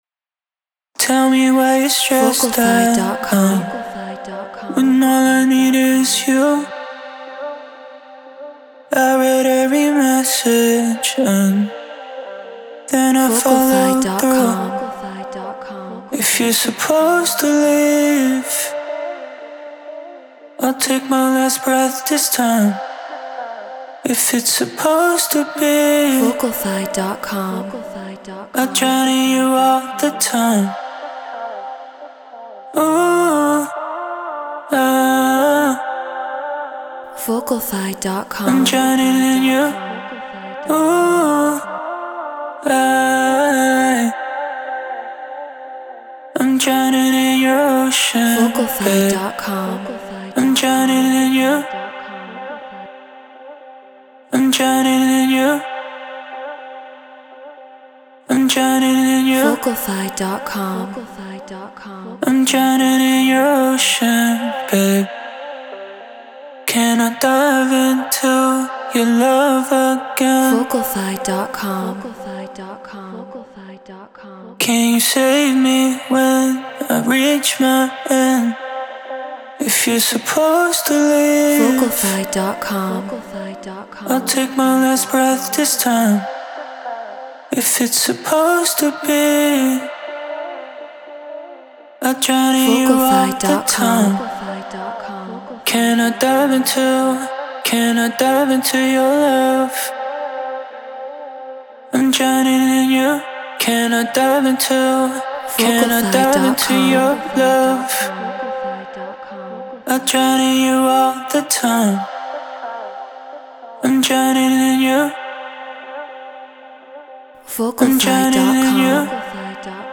Deep House 121 BPM A#maj
MXL 990 Volt 2 FL Studio Treated Room